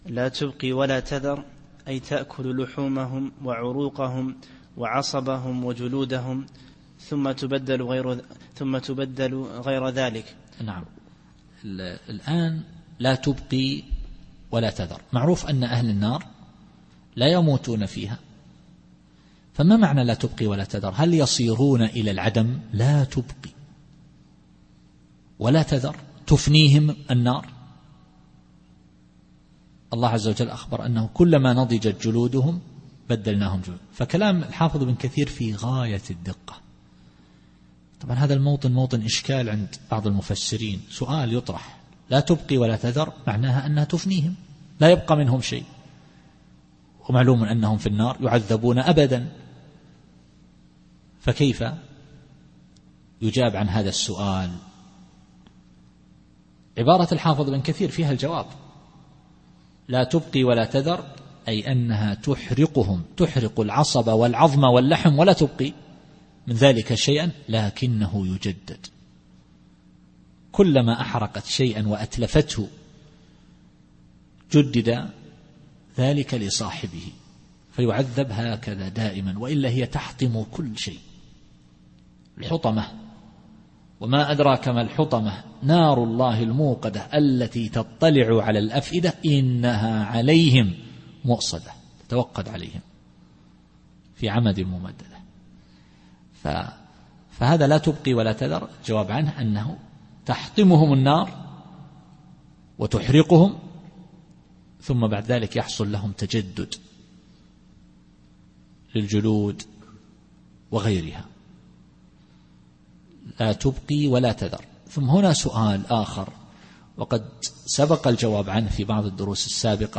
التفسير الصوتي [المدثر / 28]